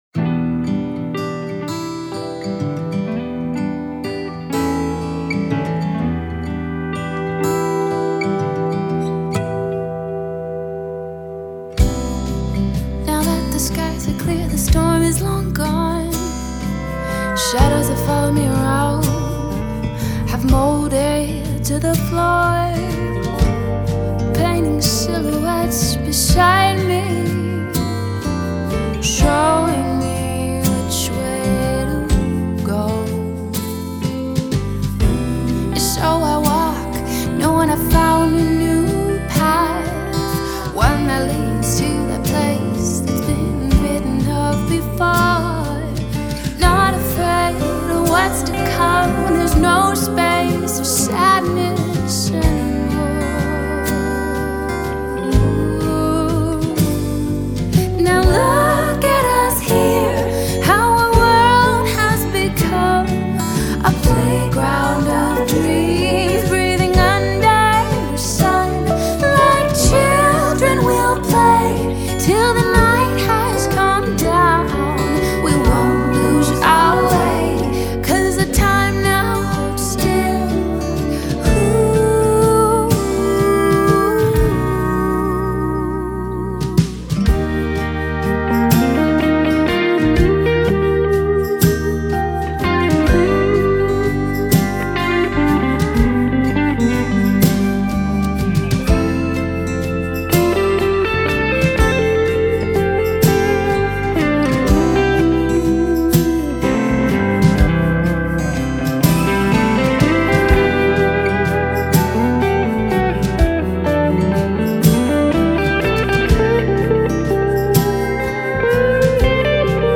pop production
We built the production from a foundation of guitars, live drums, bass, keyboards and lap steel.
bluesier backing vocals. This created the perfect combination of sweetness on the lead with a grittier texture on backups to add interest.
What once was a very basic track now includes an acoustic guitar, a couple of electric guitars, a pedal steel, Hammond and Rhodes, brush drums, electric bass, lead vocals and backing vocals.